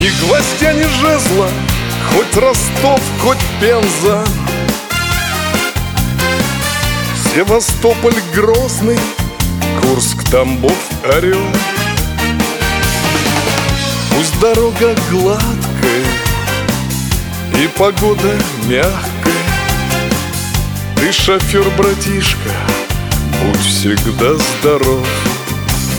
• Качество: 320, Stereo
русский шансон